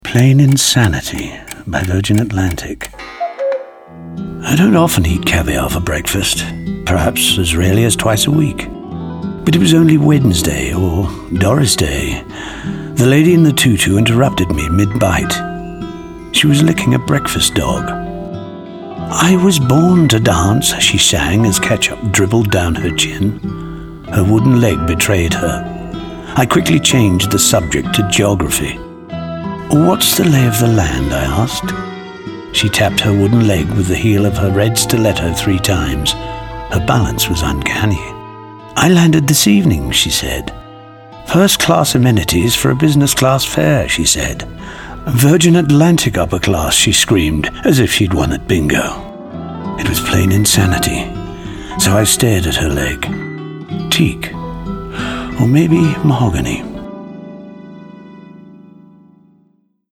Звуковой эффект: Часы с кукушкой, музыка.